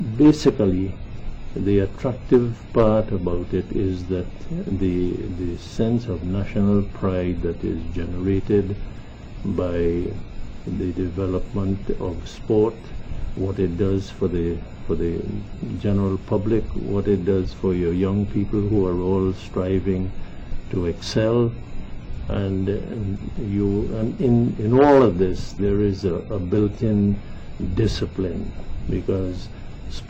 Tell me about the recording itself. l audio cassette